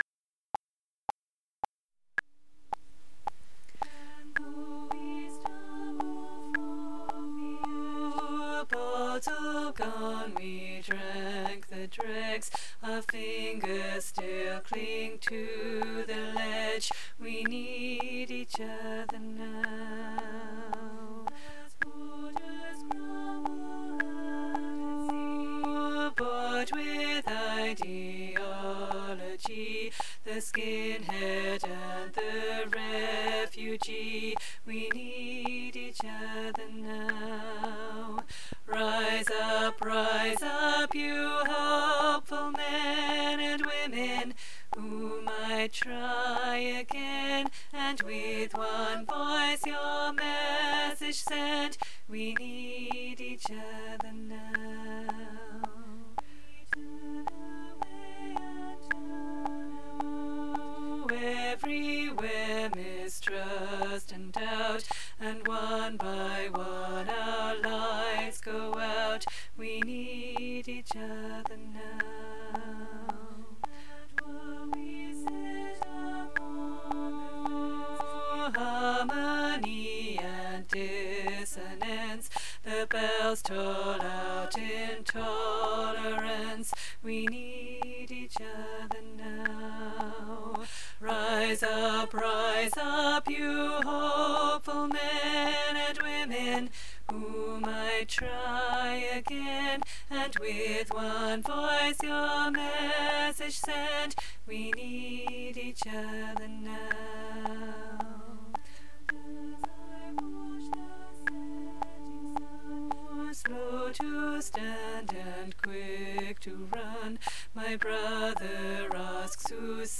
We Need Each Other Now TENOR